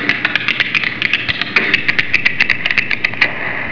TapDance
Stop/start that infernal noise by clicking on the controls!
tap.wav